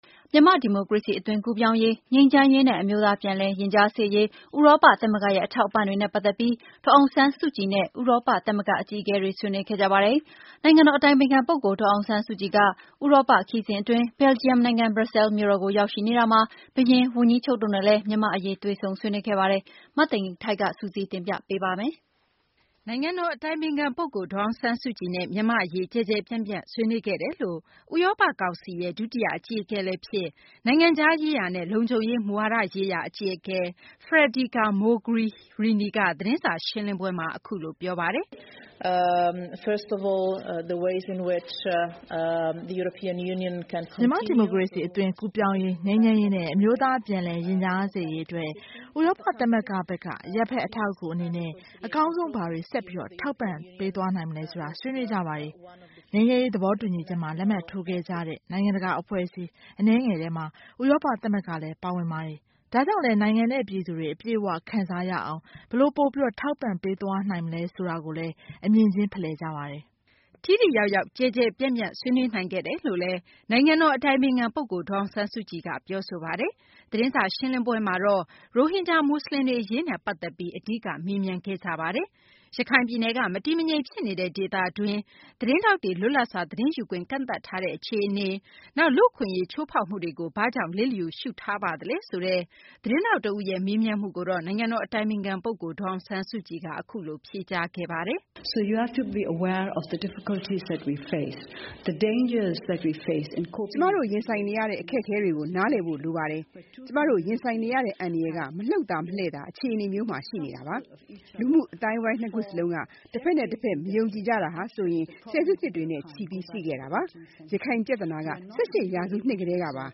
ဒေါ်အောင်ဆန်းစုကြည် - EU သတင်းစာ ရှင်းလင်းပွဲ